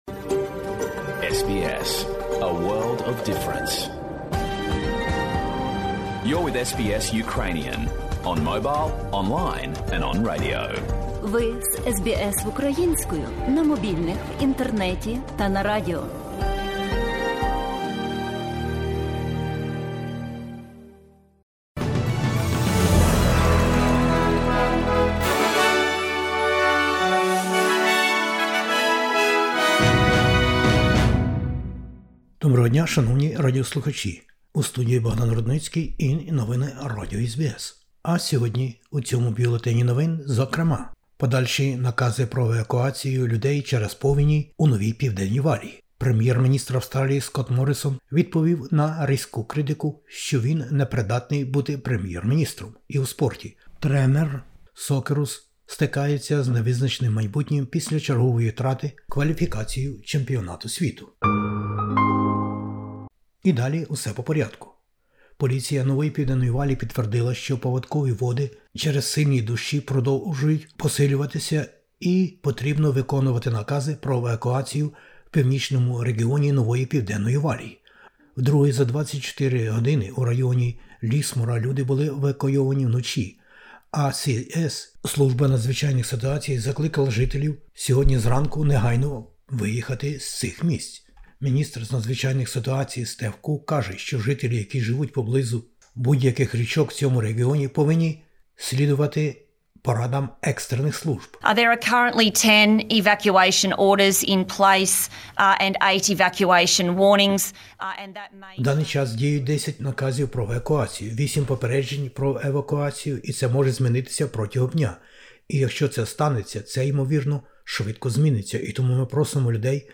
Бюлетень SBS новин українською мовою. Повені у Новій Південній Валії. Федеральний бюджет Австралії та навколо нього.